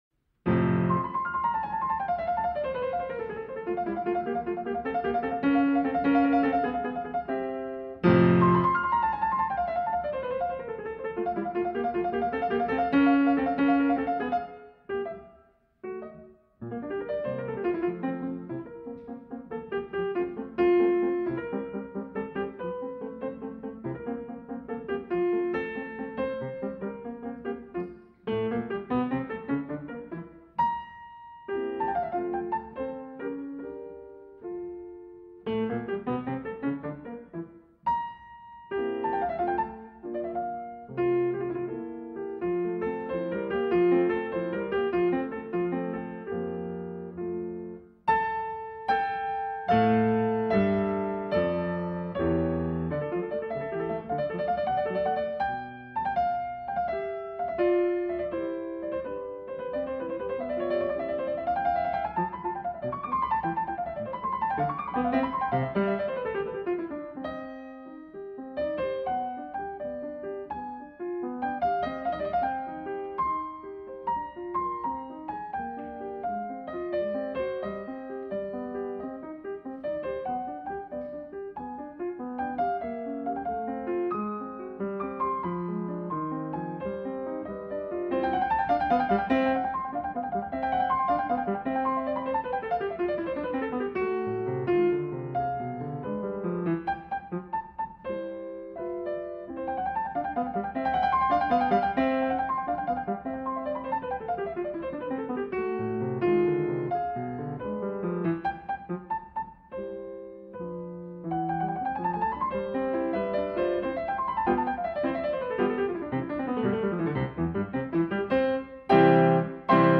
雨が静かに窓を叩くように、雨の音が思考を包み込む。
目を閉じれば広がる、木々の揺れる音、風に溶ける水のせせらぎ、夜空にひっそりと響く虫の声。
勉強BGM